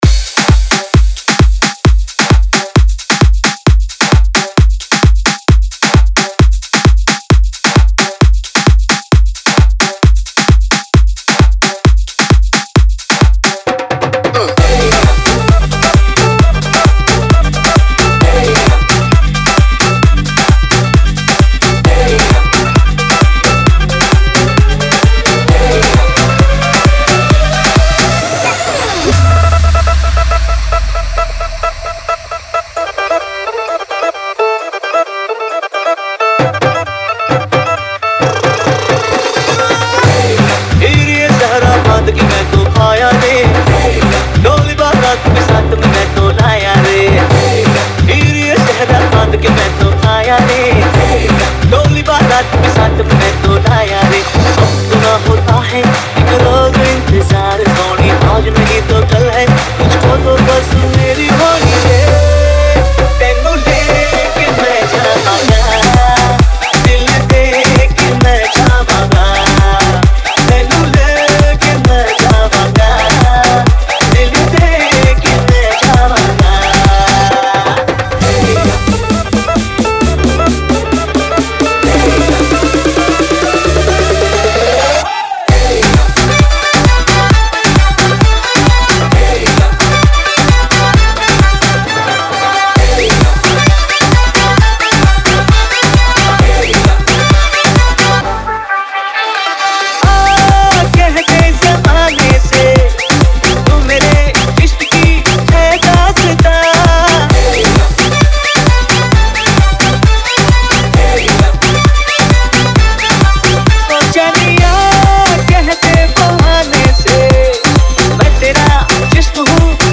2025 Bollywood Single Remixes Song Name